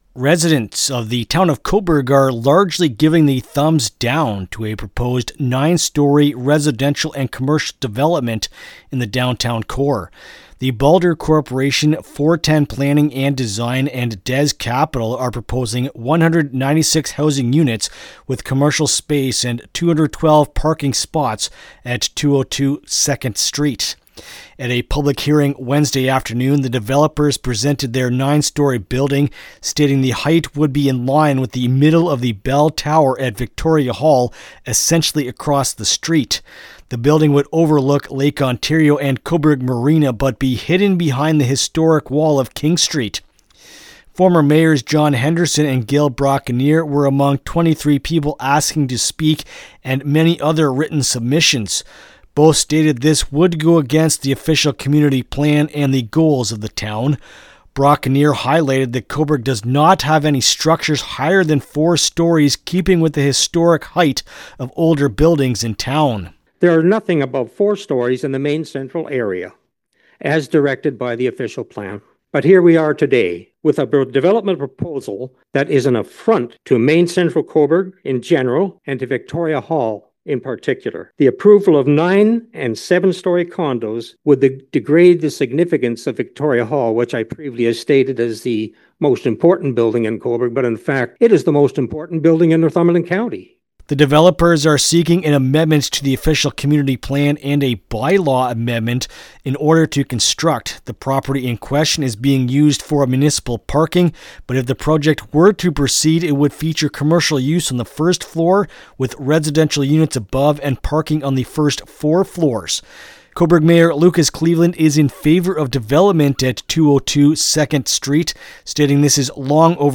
Cobourg-Housing-Development-Report-LJI.mp3